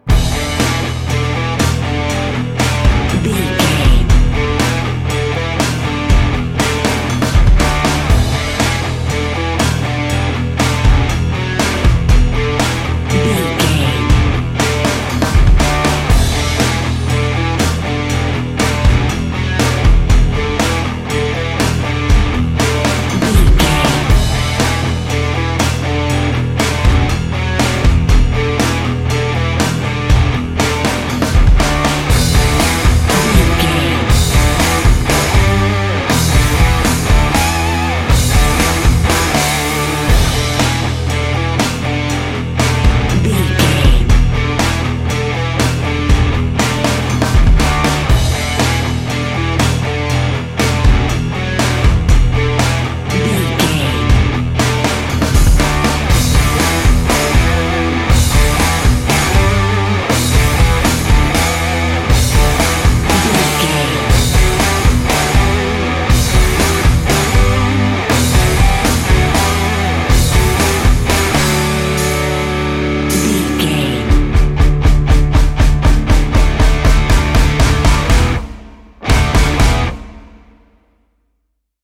Aeolian/Minor
drums
bass guitar
Sports Rock
hard rock
lead guitar
aggressive
energetic
intense
nu metal
alternative metal